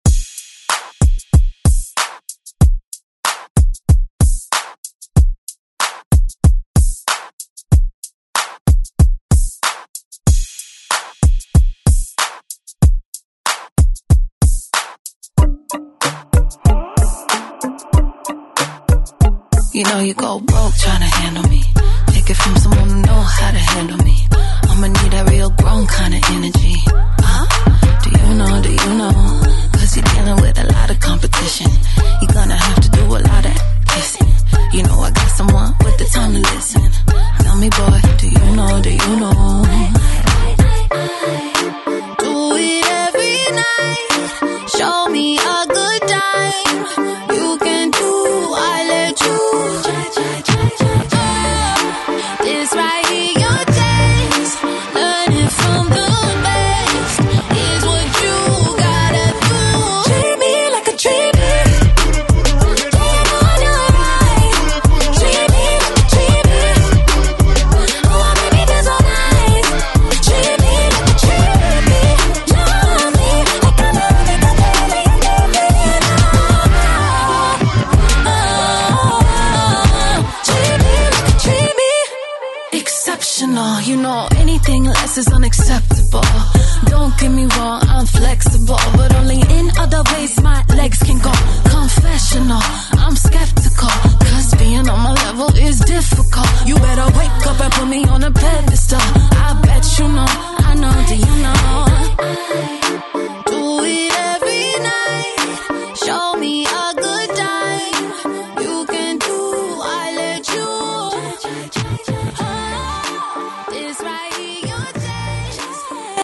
Throwback Hip Hop Rap RnB Latin Music Extended Intro Outro
100 bpm